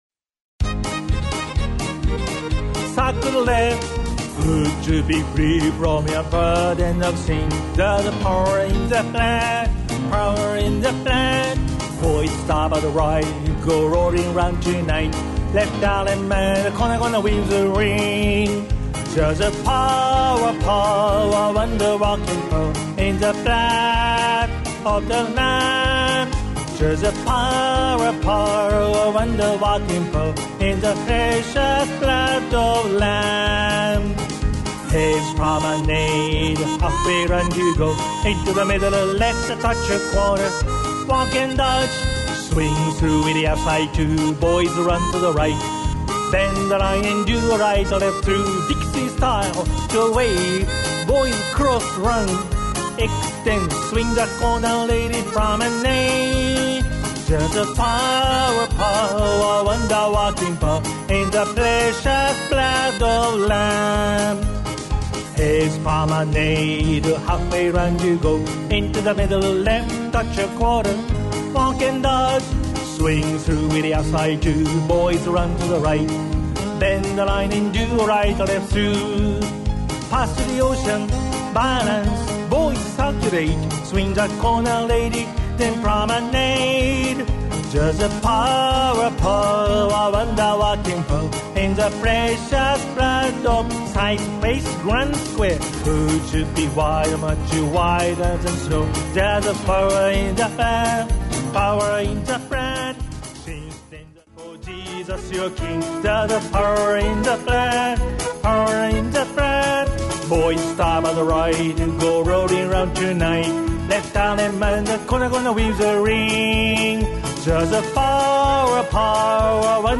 Spiritual Music